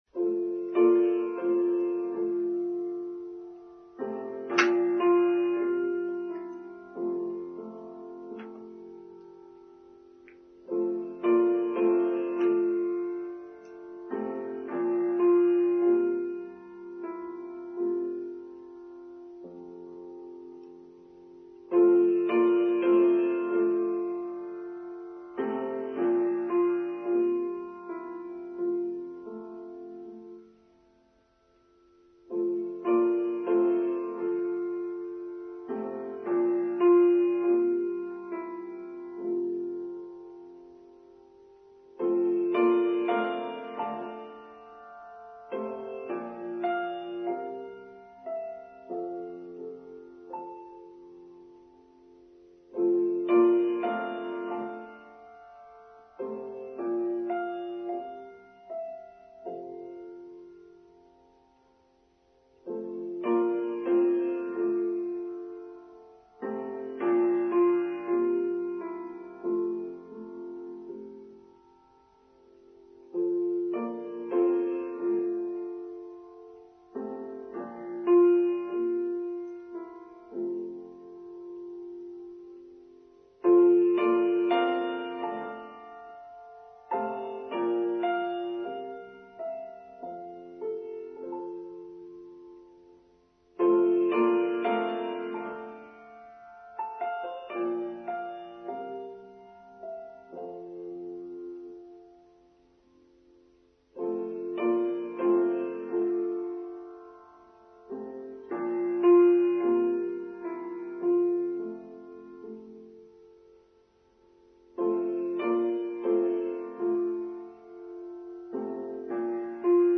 Necessary Pruning: Online Service for Sunday 20th August 2023